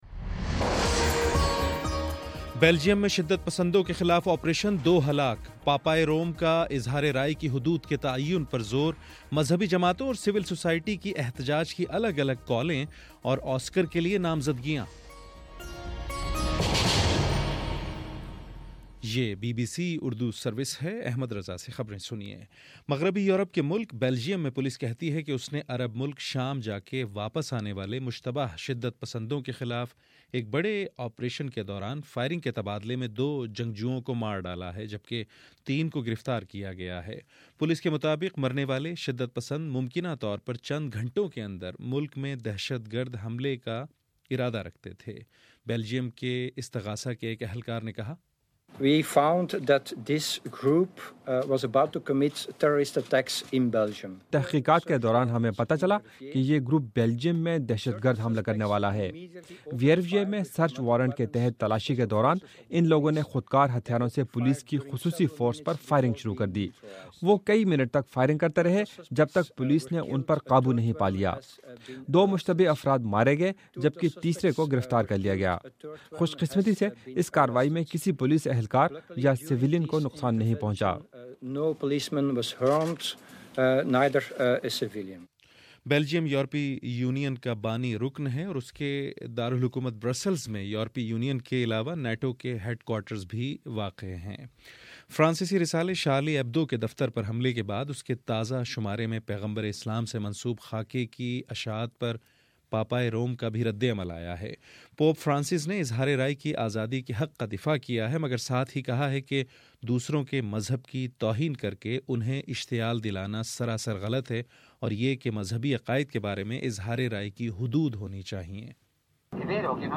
جنوری 16: صبح نو بجے کا نیوز بُلیٹن